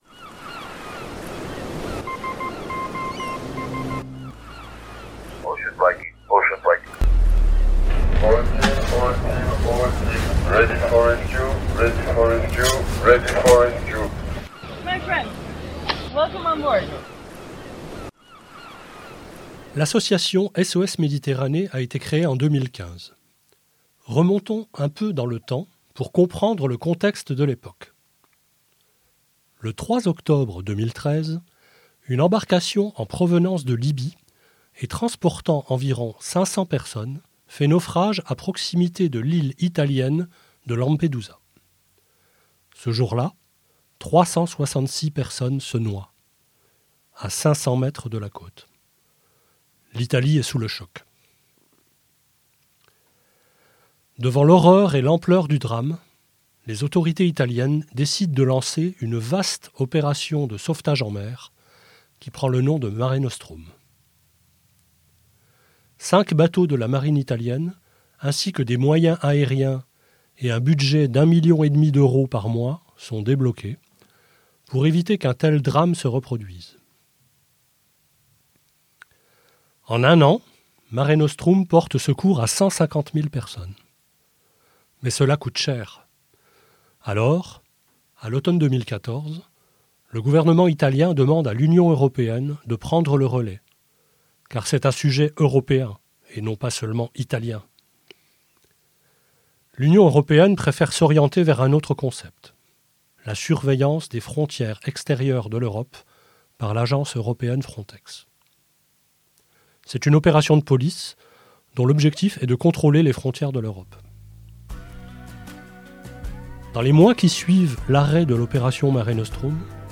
Musique : Croisières méditerranéennes (album : 5 minutes au paradis) - Bernard Lavilliers, membre du comité de soutien de SOS MÉDITERRANÉE